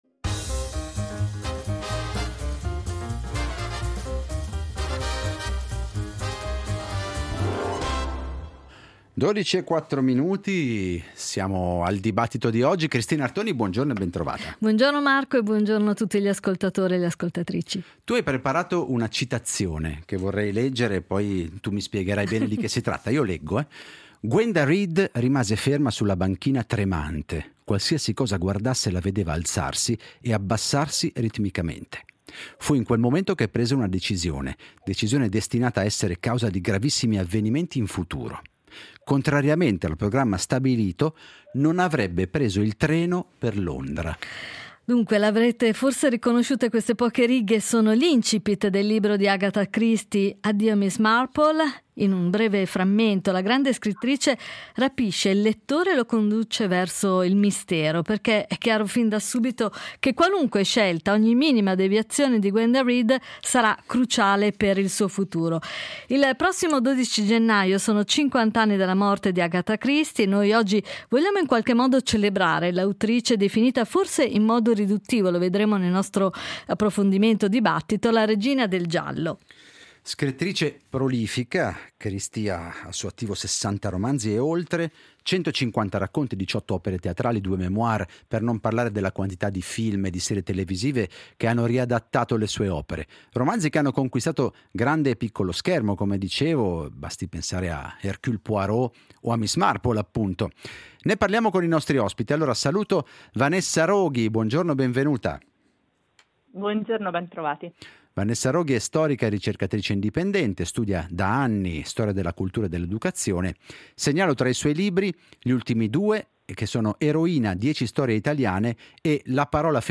Ad Alphaville abbiamo celebrato Agatha Christie con due ospiti